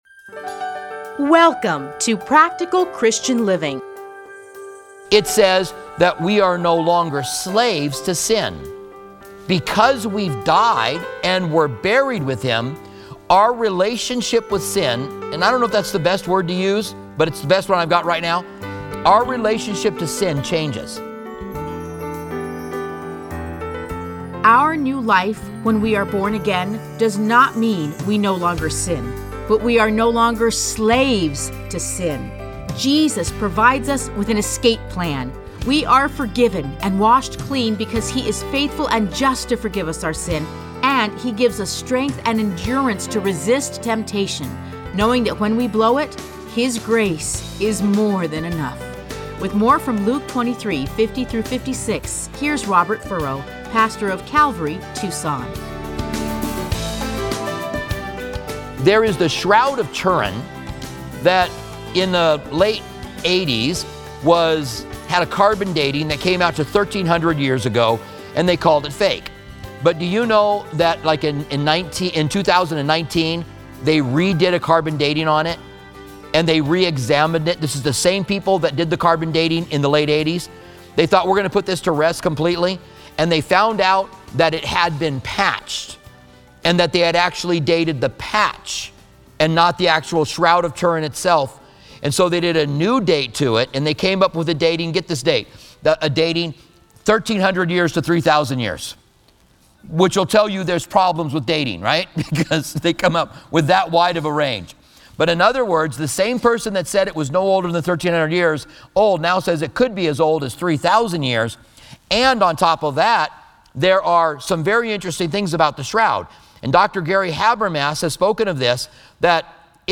Listen to a teaching from Luke 23:50-56.